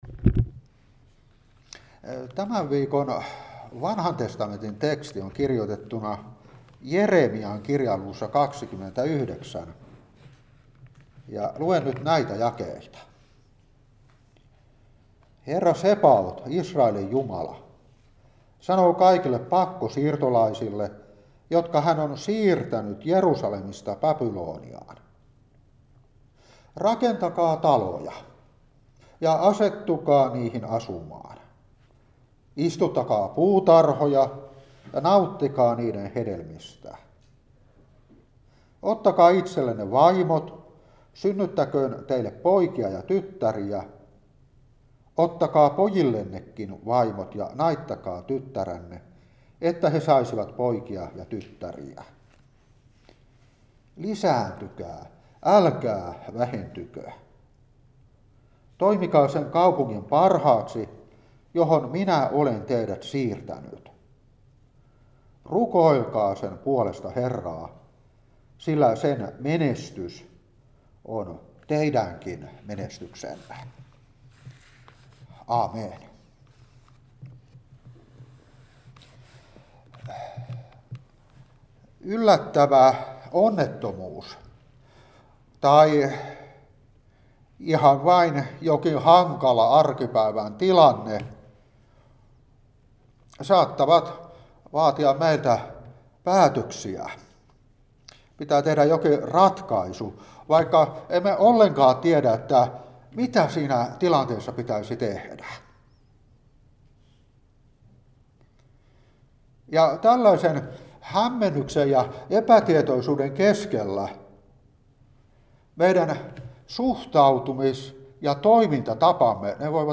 Seurapuhe 2023-11.